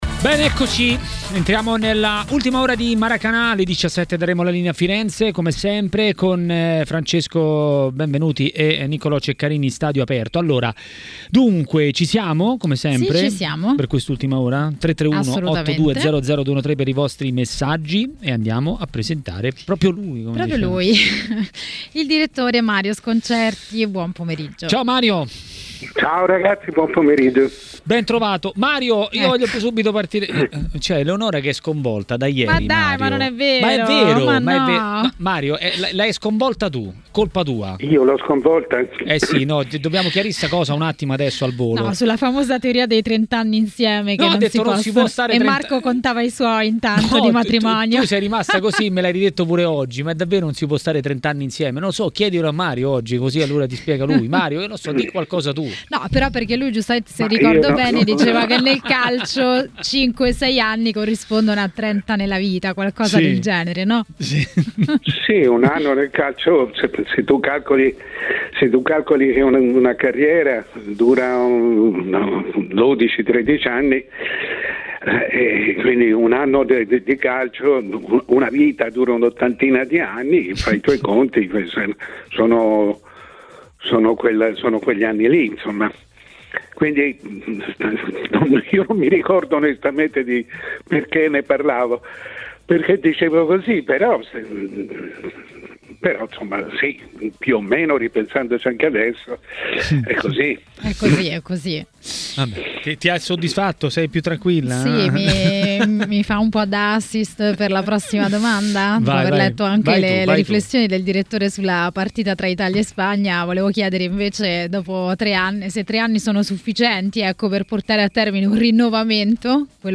A TMW Radio, durante Maracanà, è arrivato il momento del direttore Mario Sconcerti.